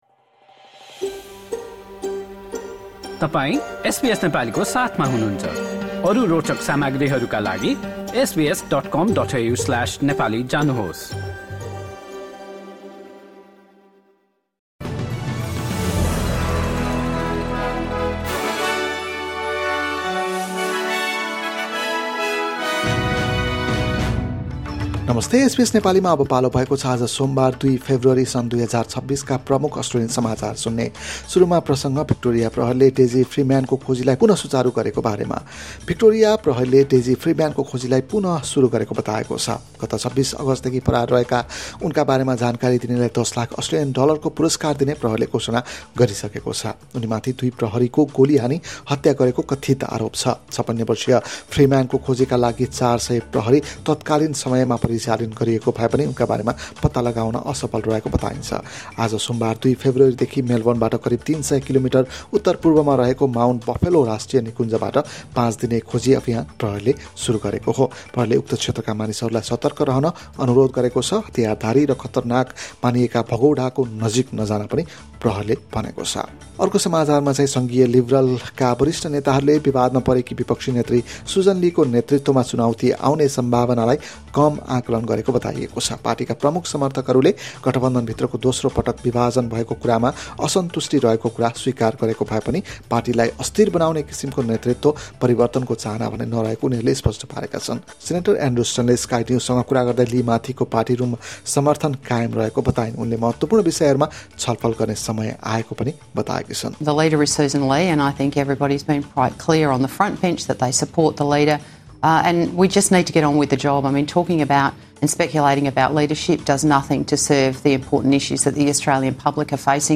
एसबीएस नेपाली प्रमुख अस्ट्रेलियन समाचार: सोमवार, २ फेब्रुअरी २०२६